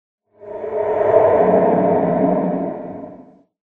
ambient / cave
cave13.ogg